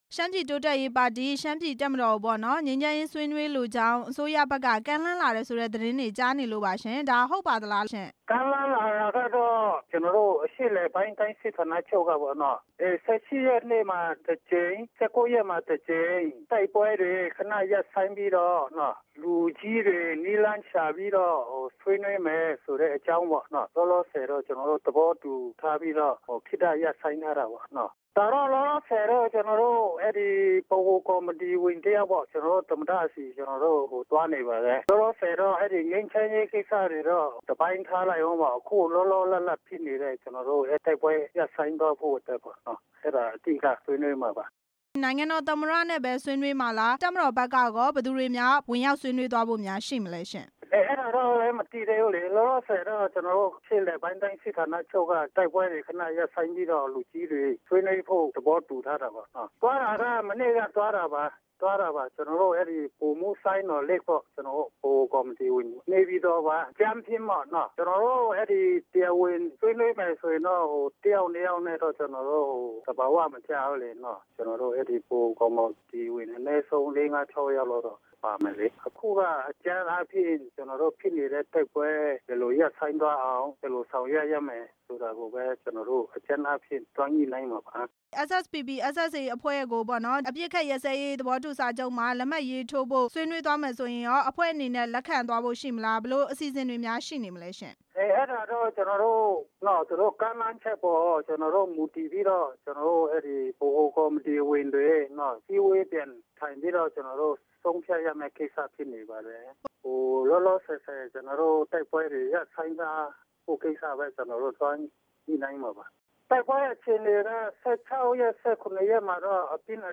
သမ္မတနဲ့ SSPP/SSA ကိုယ်စားလှယ် တွေဆုံမယ့်အကြောင်း မေးမြန်းချက်